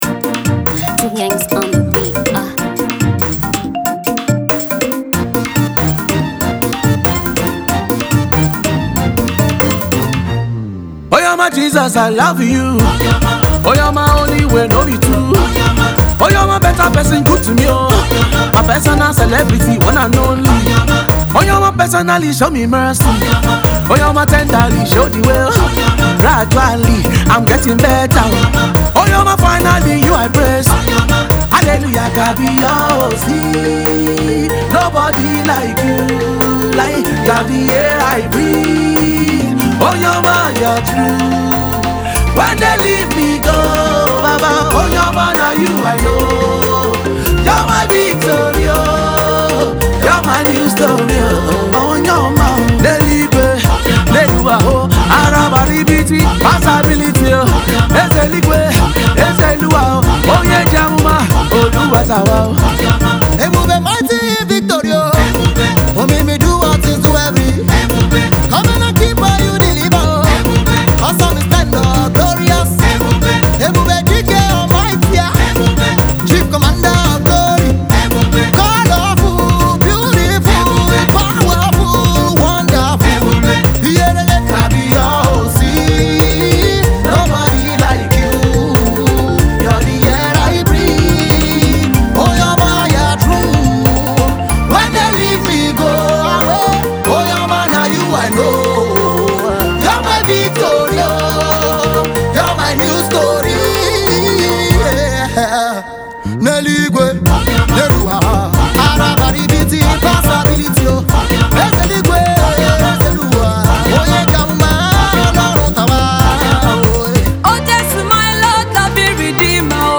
spiritual sound